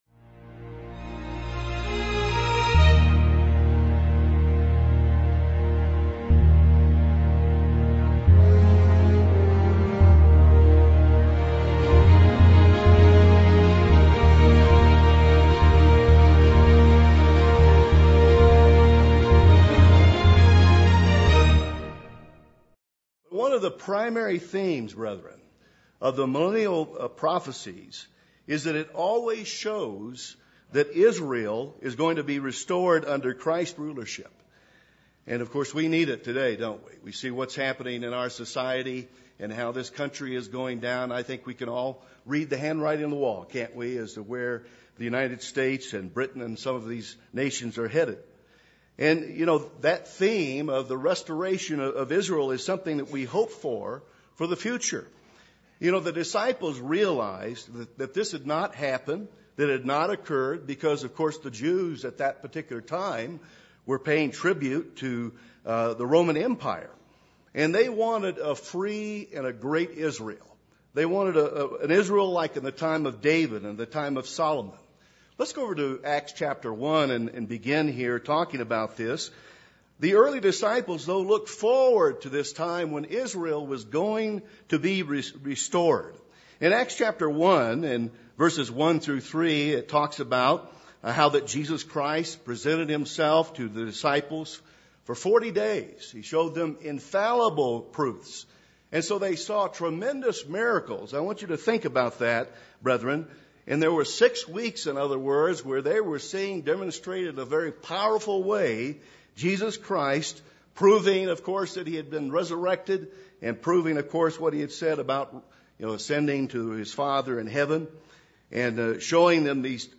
This sermon was given at the Steamboat Springs, Colorado 2009 Feast site.